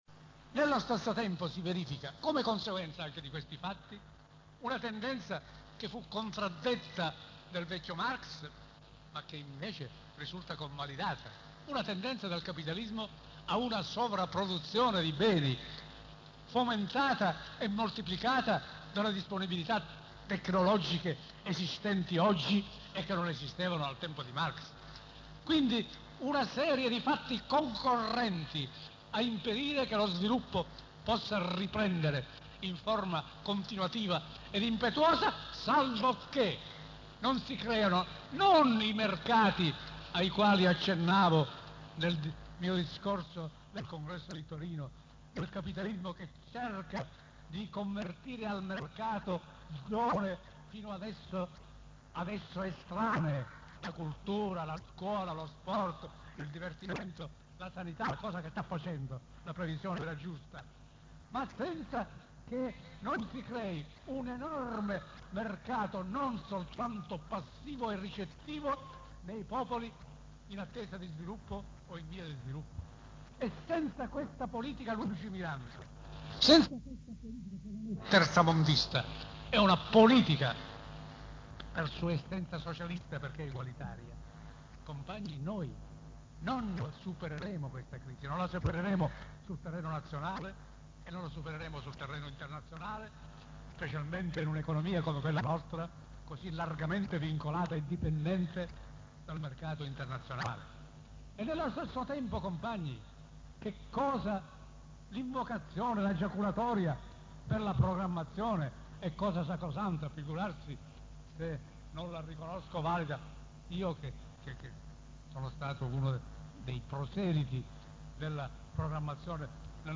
Discorso di Riccardo Lombardi, Palermo 42° congresso Psi del 22-26 aprile 1981 (terza parte - formato MP3 12,8 MB)